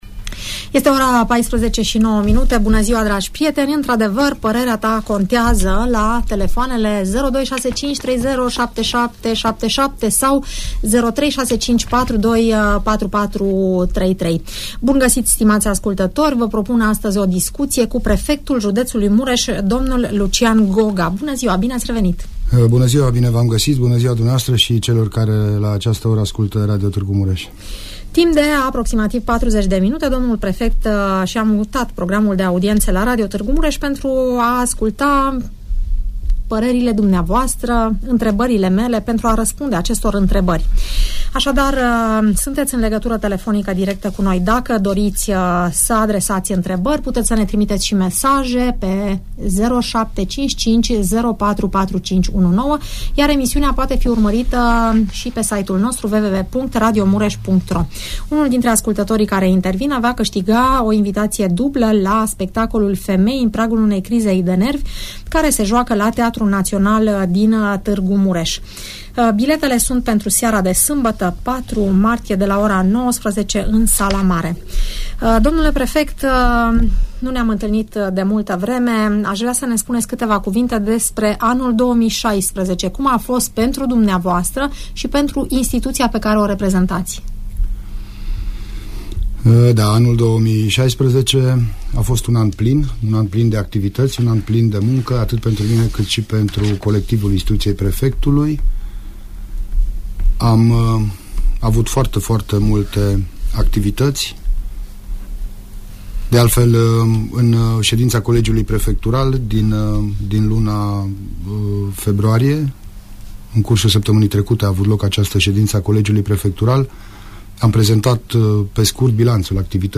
La „Parerea ta” a fost invitat prefectul judetului Mures, domnul Lucian Goga